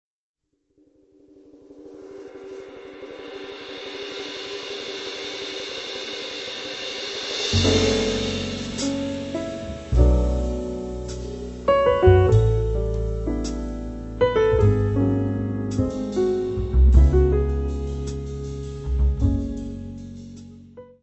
Área:  Jazz / Blues